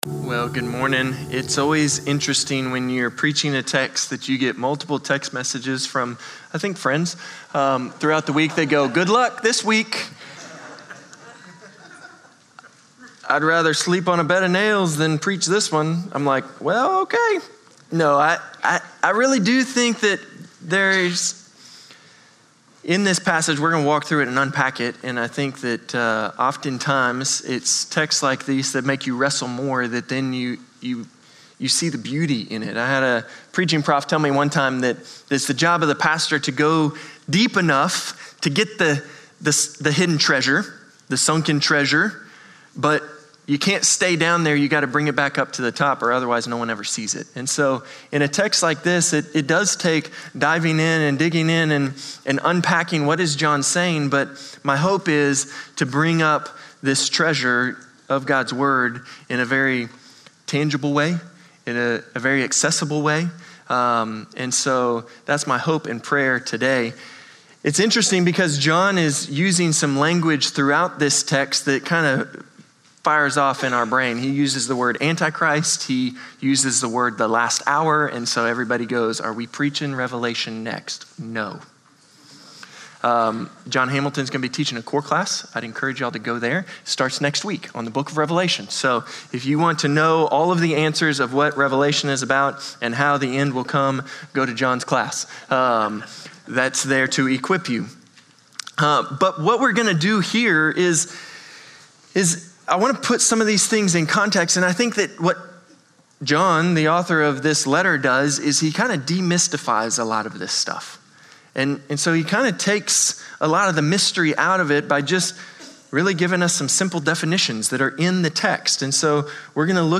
Norris Ferry Community Church sermons